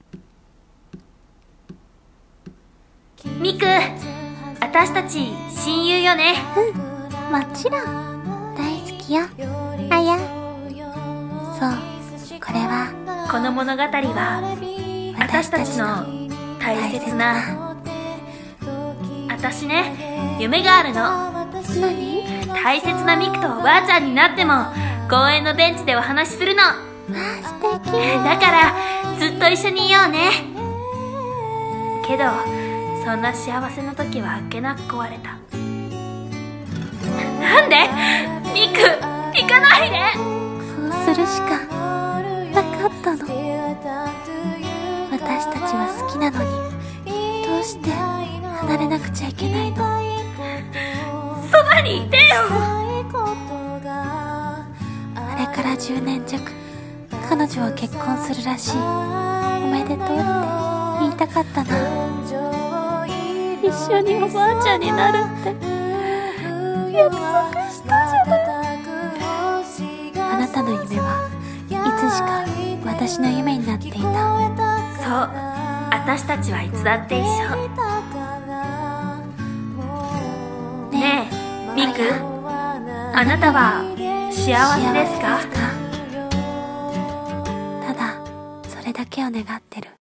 二人声劇「思い出の私たちは美しいままで